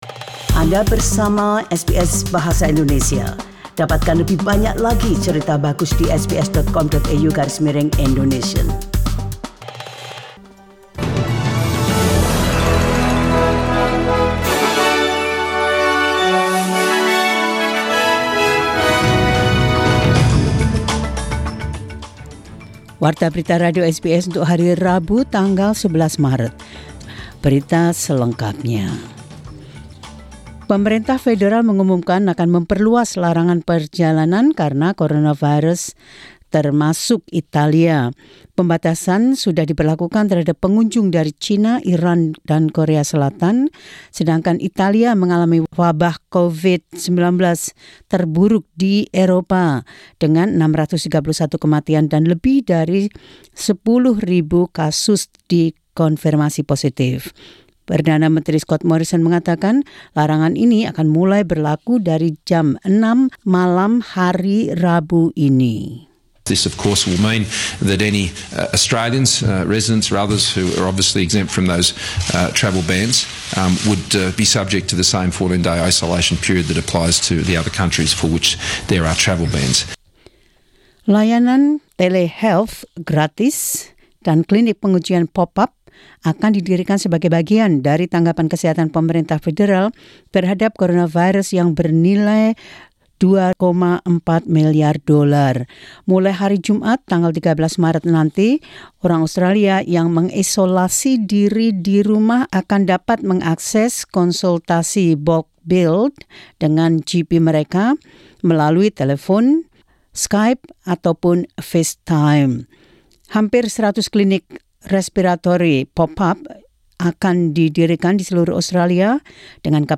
SBS Radio News in Indonesian 11 Mar 2020.